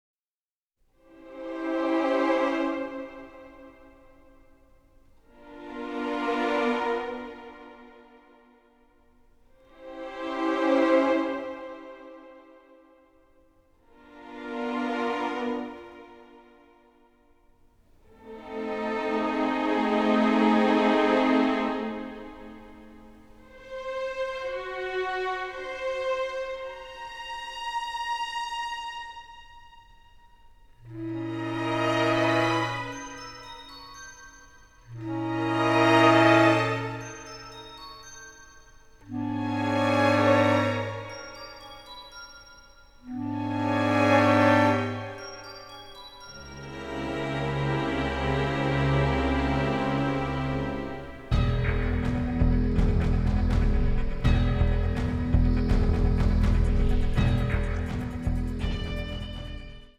suspense score